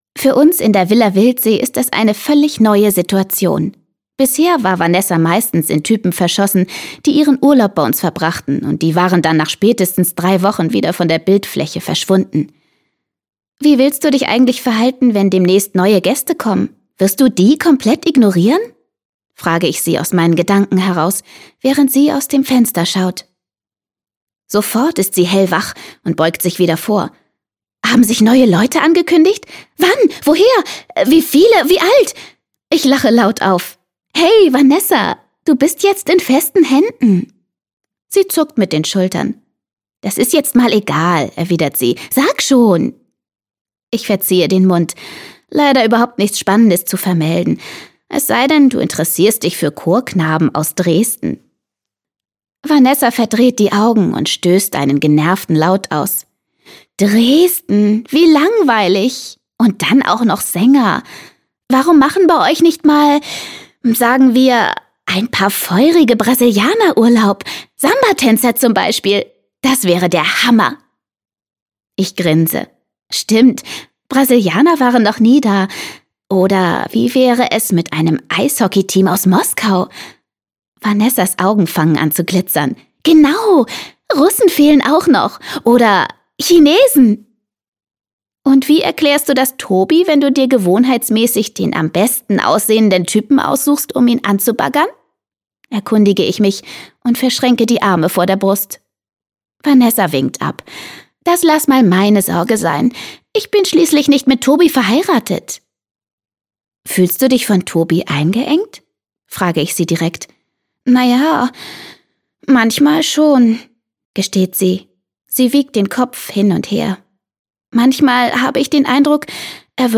Freche Mädchen: Traumküsse aus Amerika - Martina Sahler - Hörbuch